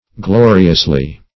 Glo"ri*ous*ly, adv.